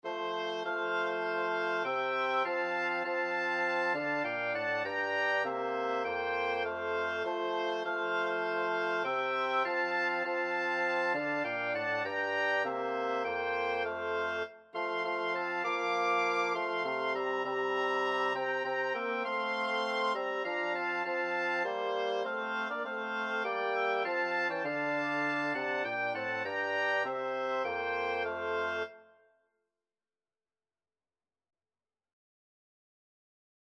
Oboe 1Oboe 2Bassoon 1Bassoon 2
3/4 (View more 3/4 Music)
Wind Quartet  (View more Easy Wind Quartet Music)